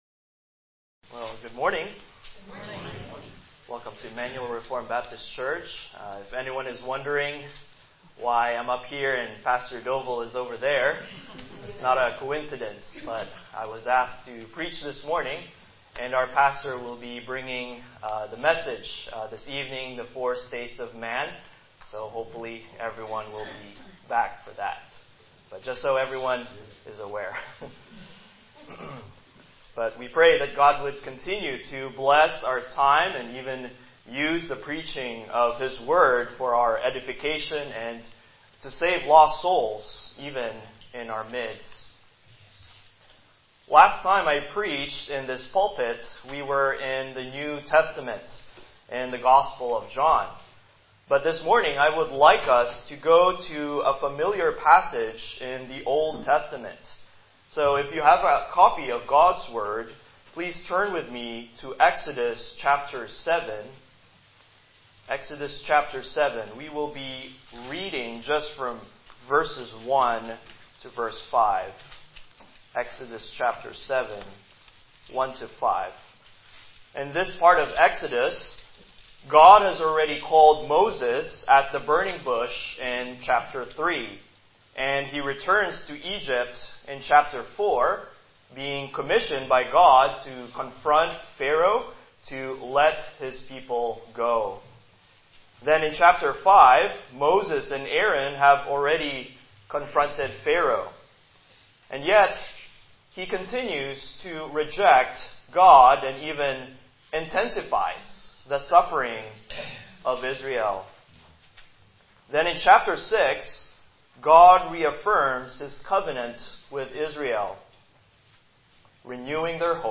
Passage: Exodus 7:1-5 Service Type: Morning Worship « Chapter 17.1